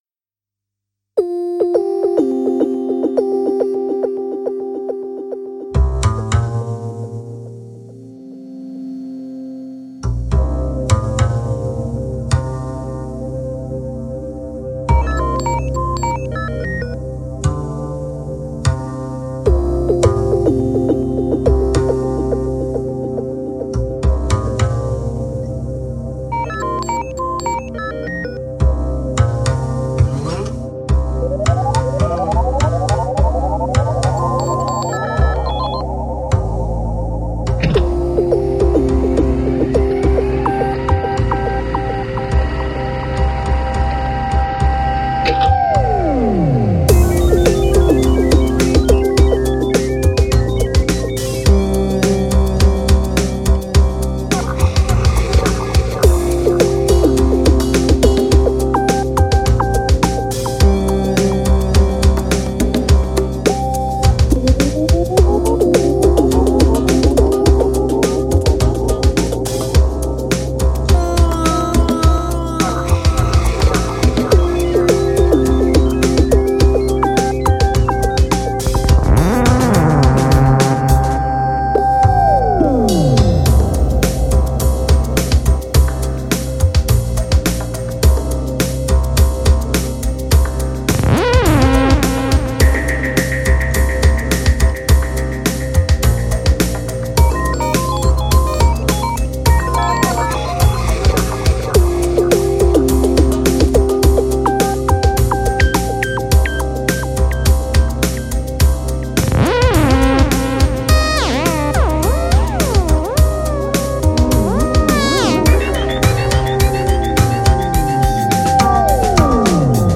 Žánr: Electro/Dance
svým sytým hlasem